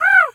pgs/Assets/Audio/Animal_Impersonations/crow_raven_call_squawk_04.wav at master
crow_raven_call_squawk_04.wav